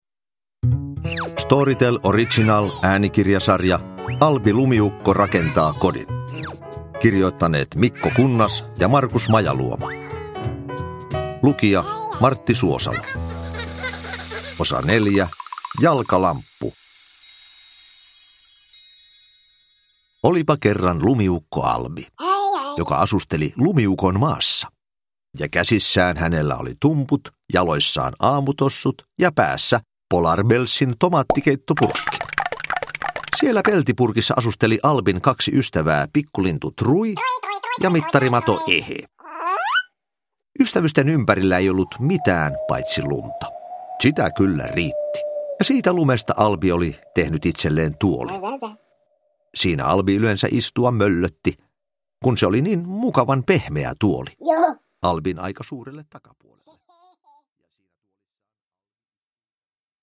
Uppläsare: Martti Suosalo